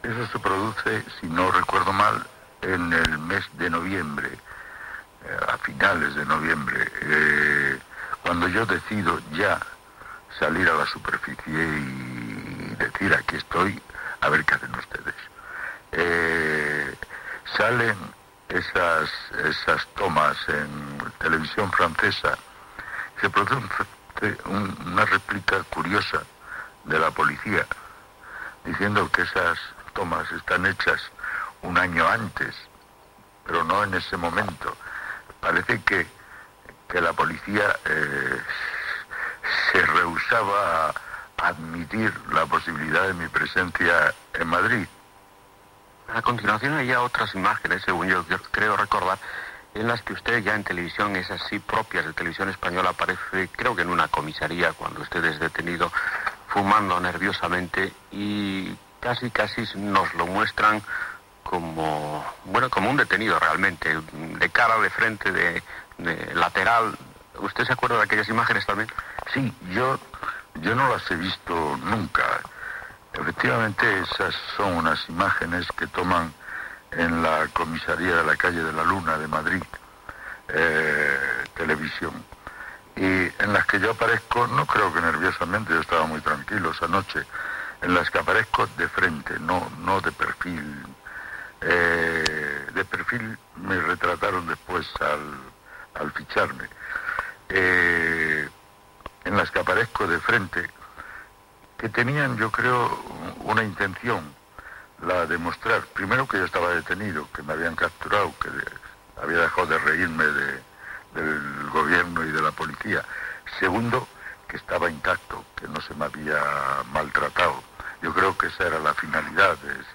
Entrevista al polític del Partido Comunista de España Santiago Carrillo que recorda la seva detenció, disfressat, el mes de novembre de 1976
Informatiu